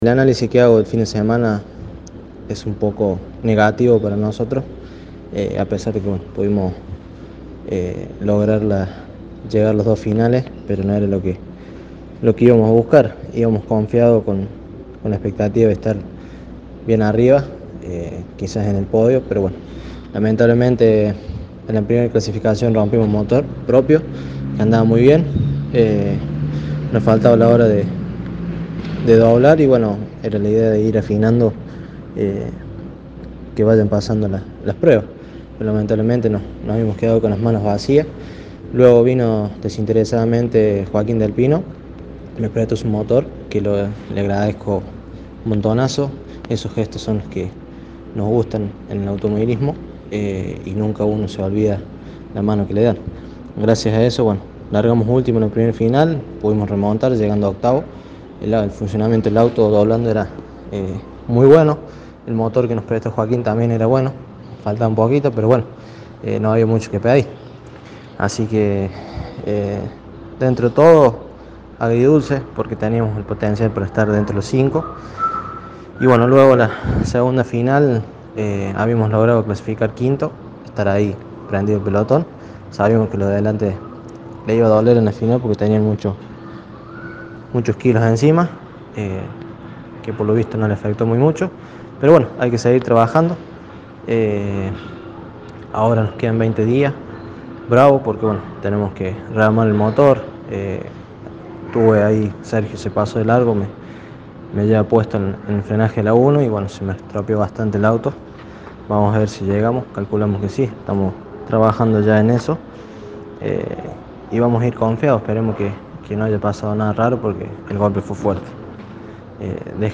En diálogo con Poleman Radio, analizó el fin de semana